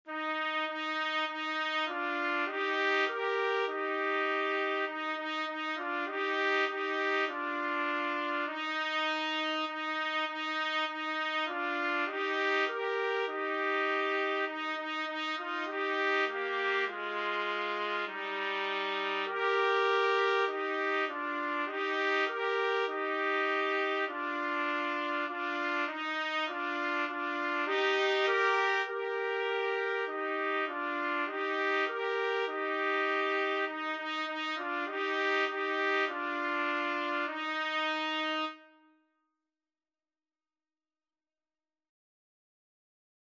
Trumpet 1Trumpet 2
4/4 (View more 4/4 Music)